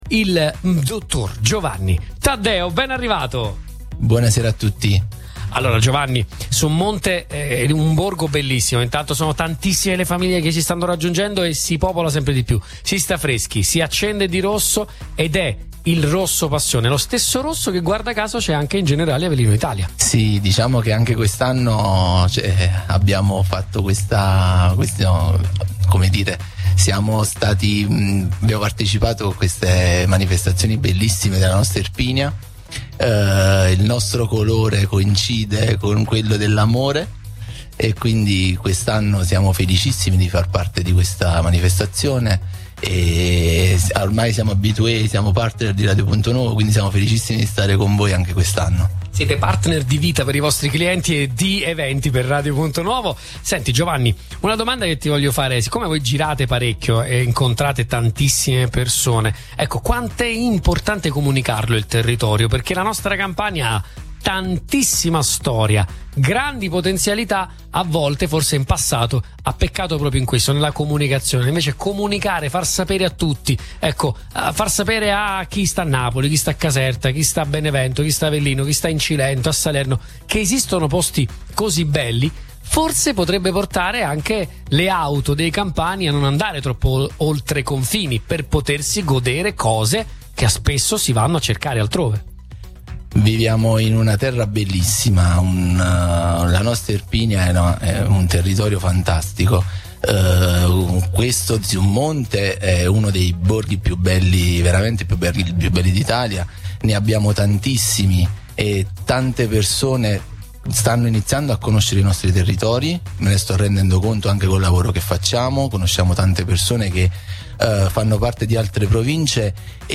Sabato 21 giugno, in occasione del solstizio d’estate, il borgo di Summonte, tra i più belli d’Italia, ha ospitato una nuova edizione della Notte Romantica, evento patrocinato dal Comune di Summonte, in collaborazione con Generali Avellino Italia e Radio Punto Nuovo.